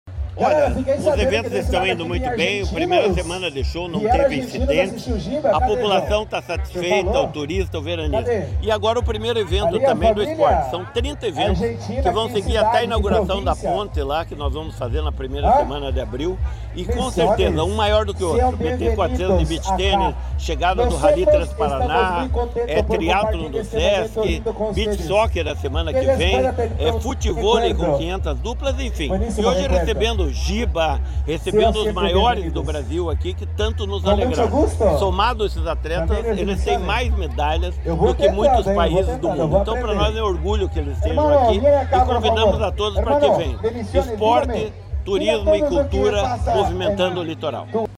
Sonora do secretário estadual do Esporte e coordenador do Verão Maior Paraná, Helio Wirbiski, sobre o Desafio Olímpico de Vôlei de Praia